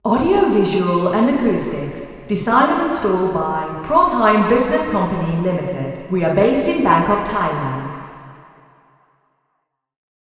Below are auralizations for the four test positions in the study.
Receiver 01 Female Talker